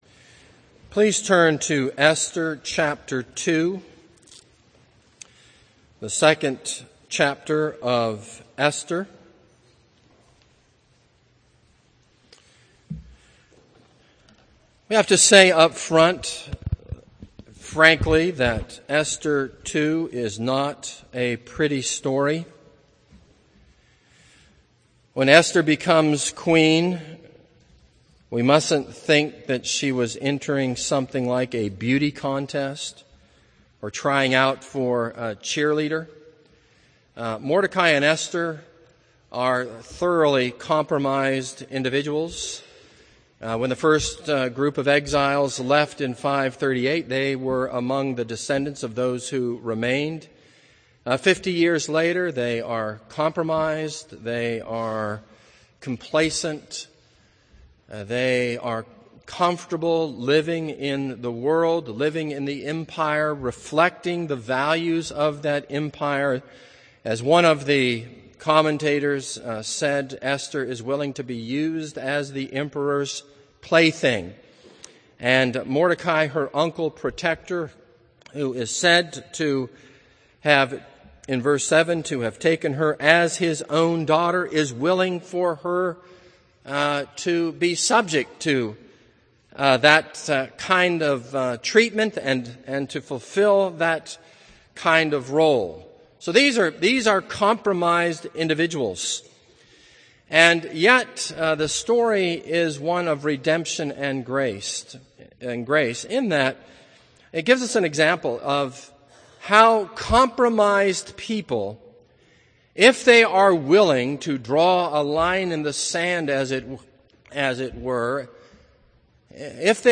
This is a sermon on Esther 2.